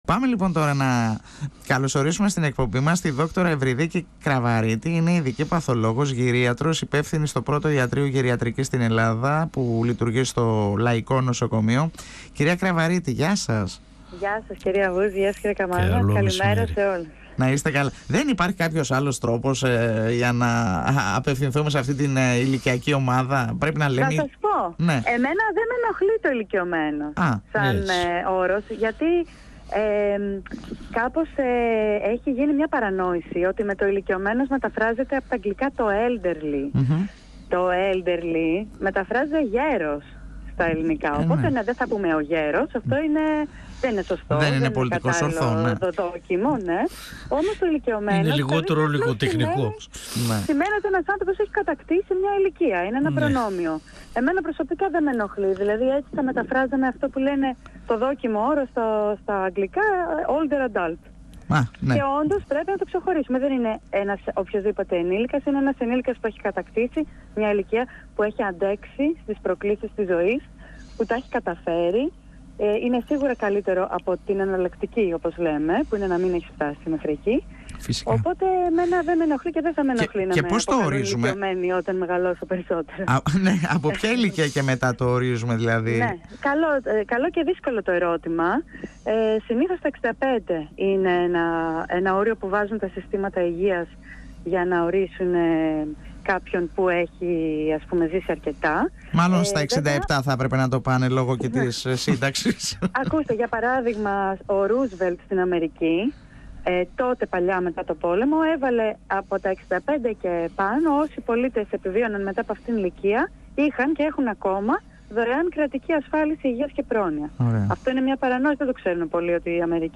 Η σημερινή γενιά των 80 και 90 είναι παιδιά της Κατοχής και είναι ιδιαίτερα ανθεκτική». 102FM Εδω και Τωρα Συνεντεύξεις ΕΡΤ3